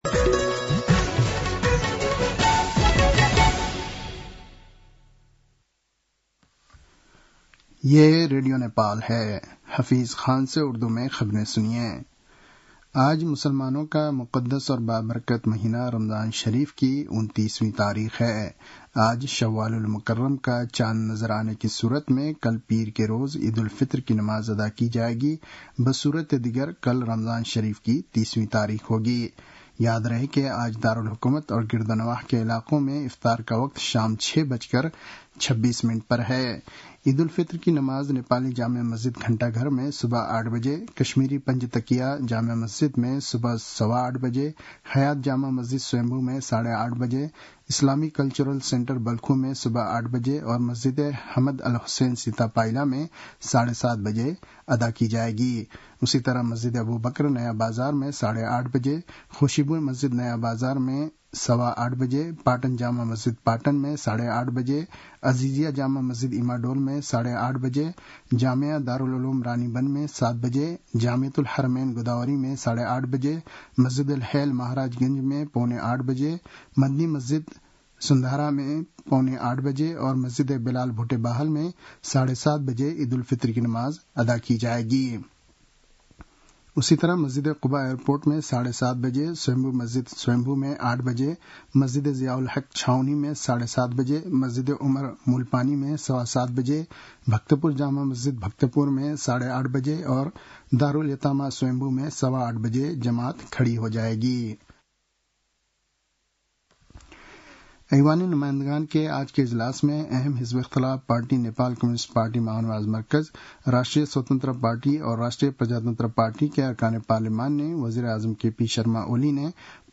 उर्दु भाषामा समाचार : १७ चैत , २०८१